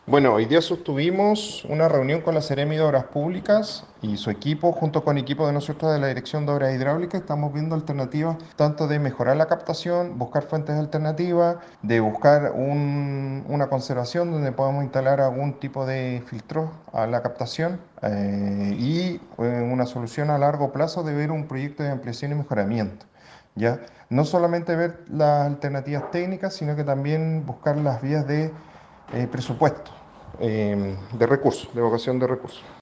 El director de la DOH, Sergio Asenjo, señaló que están buscando fuentes alternativas para brindar del vital elemento a la comunidad.